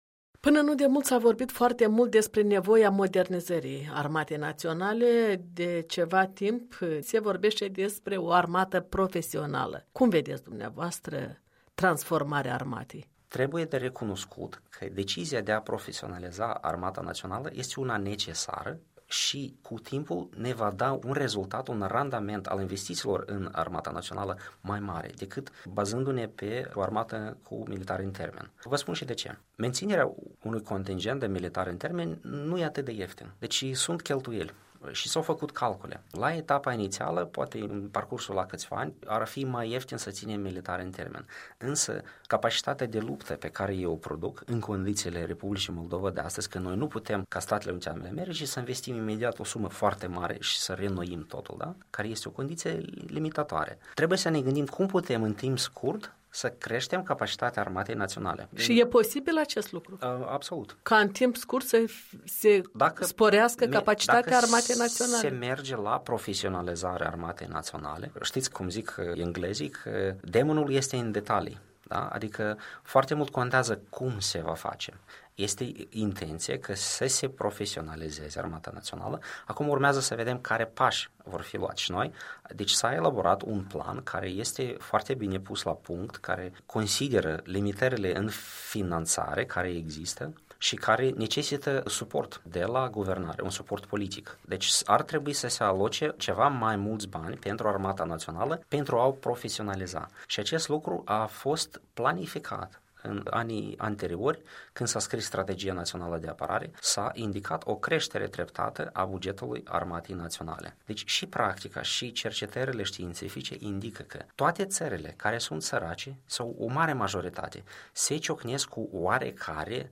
Interviu cu secretar de stat responsabil politica de apărare şi cooperare internaţională în Ministerul apărării.